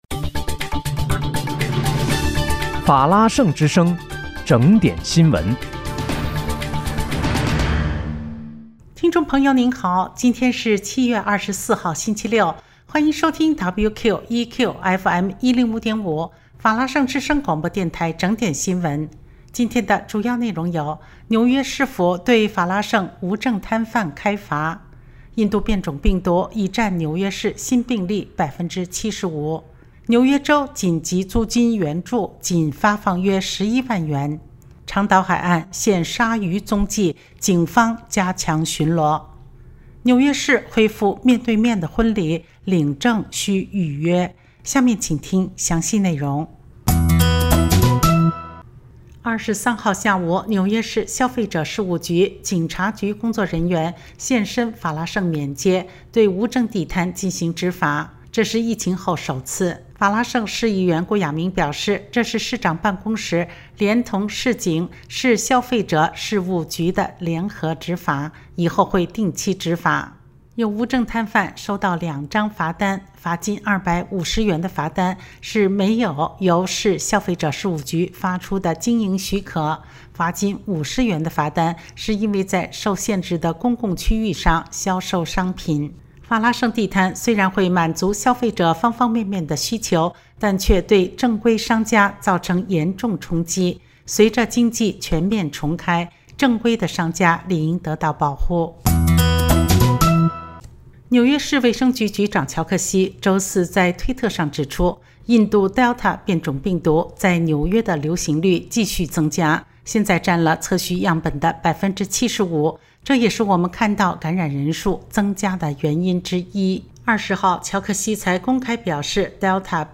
7月24（星期六）纽约整点新闻